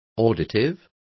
Complete with pronunciation of the translation of auditive.